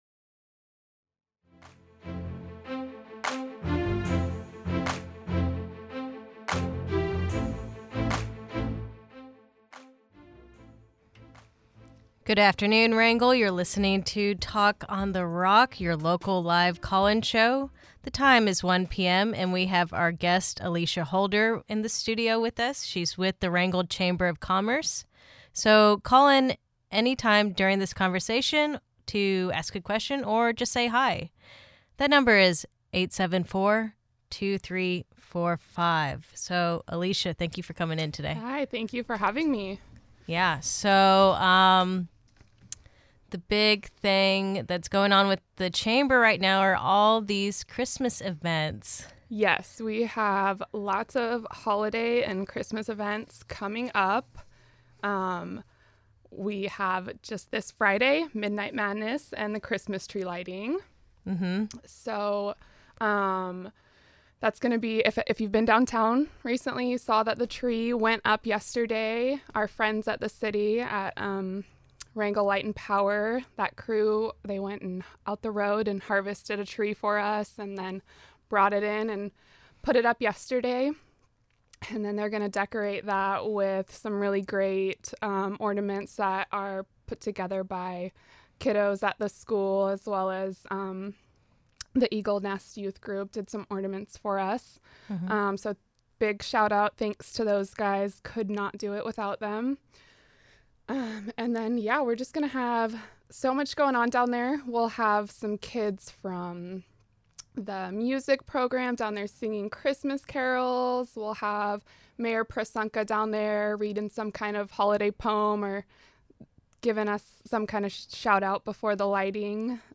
Talk on the Rock is Wrangell's live call-in show.